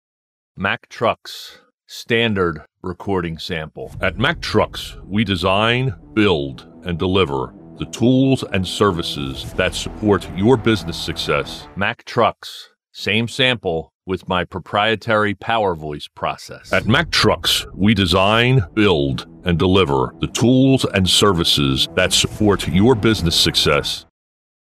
Never AI, always authentic.
Professional-grade equipment and acoustic treatment deliver broadcast-ready audio that rivals major market studios in New York and Los Angeles.
Standard vs PowerVoice Demo